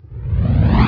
teleport2_reverse.wav